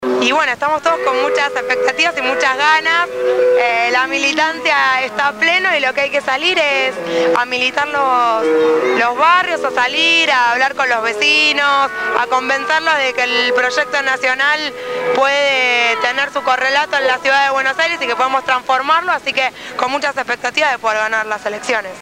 El sábado 4 de junio se llevó adelante la jornada solidaria «Somos Ambiente» en la Villa 21-24.